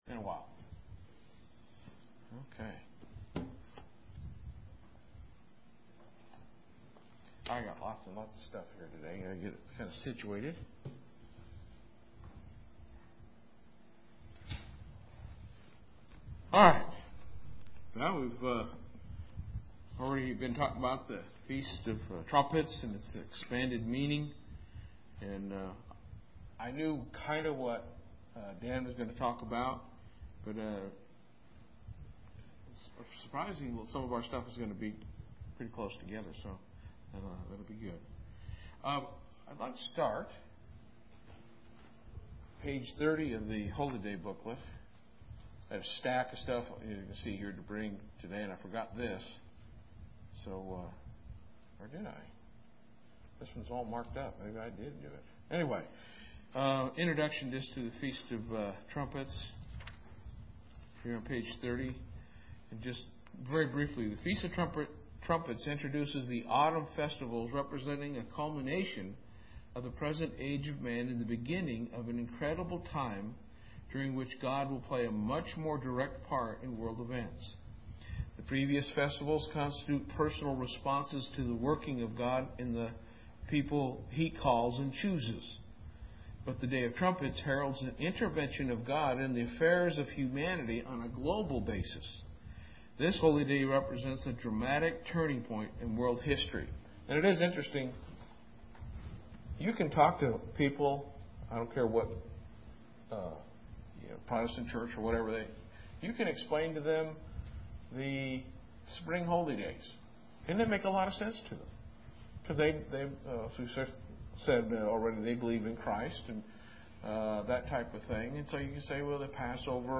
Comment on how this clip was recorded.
Given in Lubbock, TX